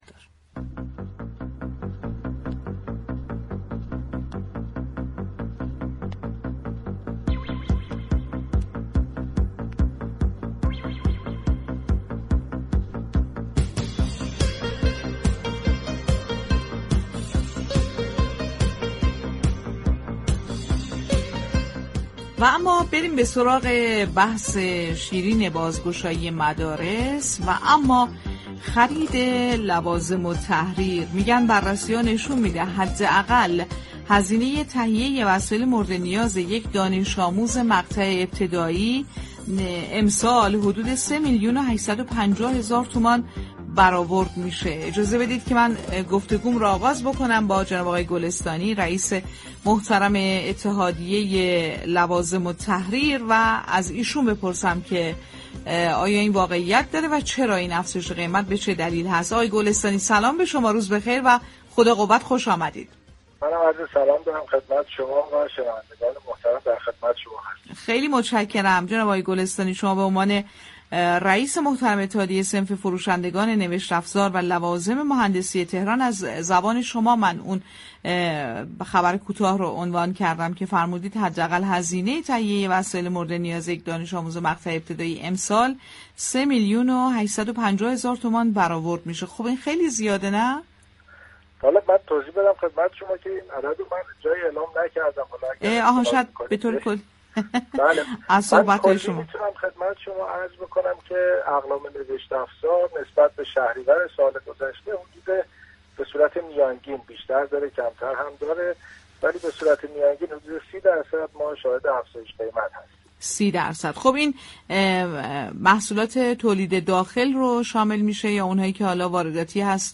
گفتگوی رادیویی